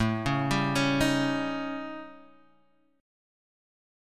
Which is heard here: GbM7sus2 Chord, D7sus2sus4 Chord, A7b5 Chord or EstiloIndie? A7b5 Chord